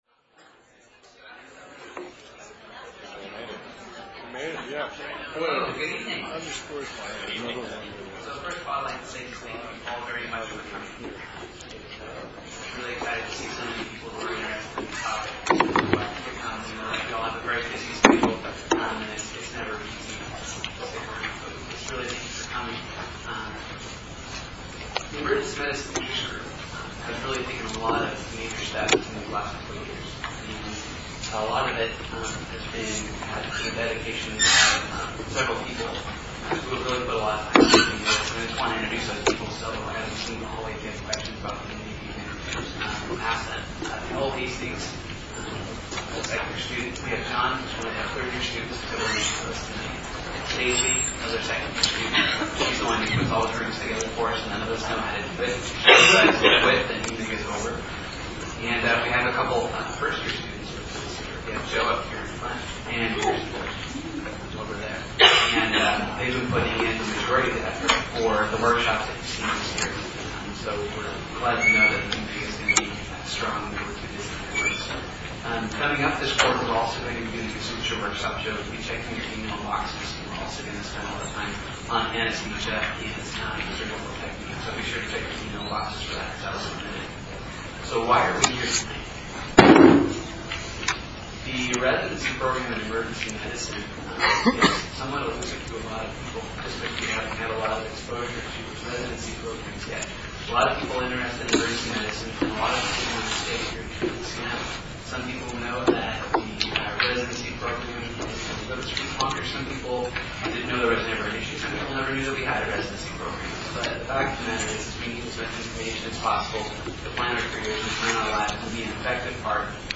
( 150MB WMV file ) or ( 12MB MP3 file ) The quality is not great, but if you turn it up you can tell what is happening. The UW EMIG sponsored a discussion between the administration of both the UW School of Medicine and Harborview Medical Center regarding the establishment of an Emergency Medicine Residency Program.